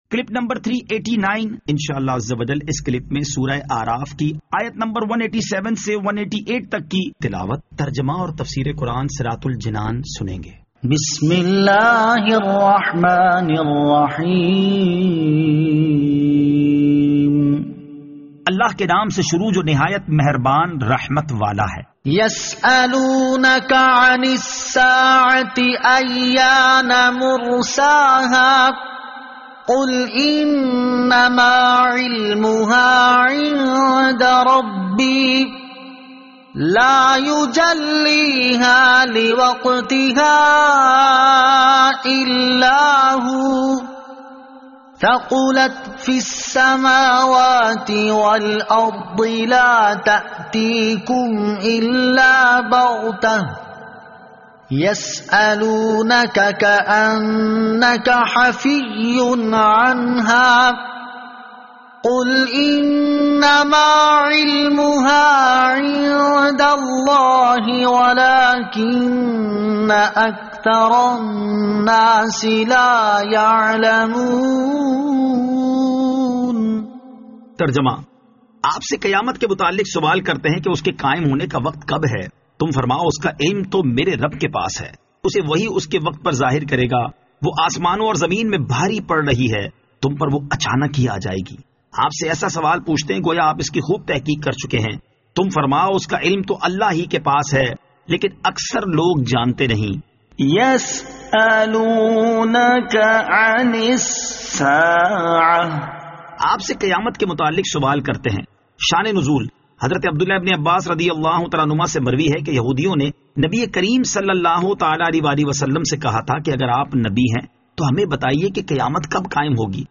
Surah Al-A'raf Ayat 187 To 188 Tilawat , Tarjama , Tafseer